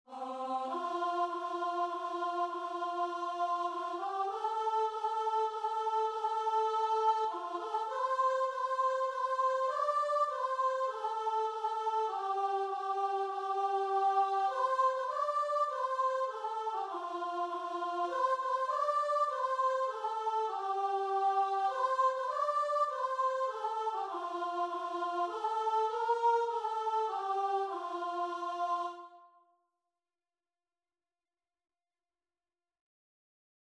3/4 (View more 3/4 Music)
Classical (View more Classical Guitar and Vocal Music)